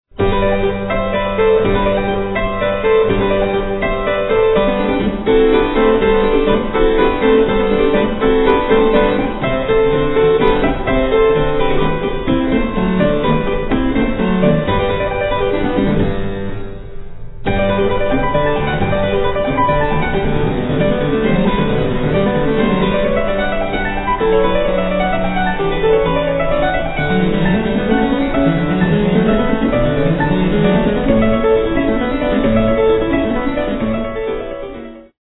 harpsichordist
Sonata for keyboard in G major, K. 201 (L. 129) - 4:01